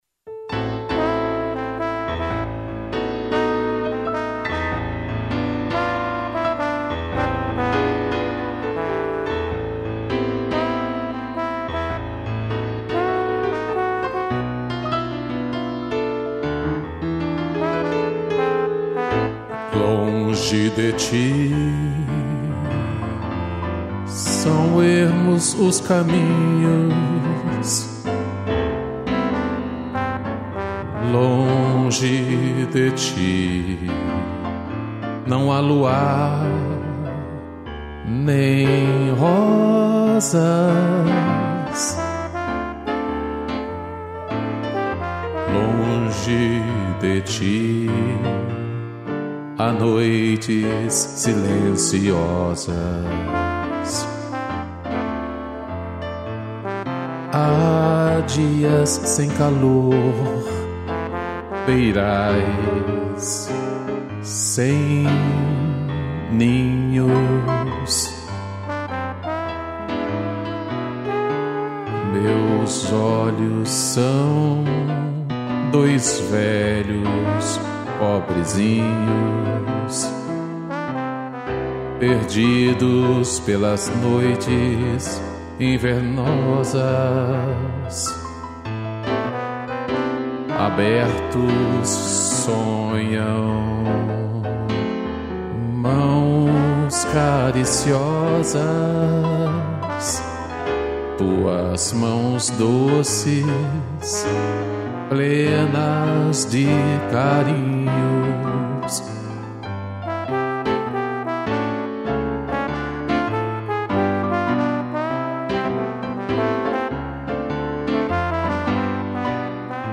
2 pianos e trombone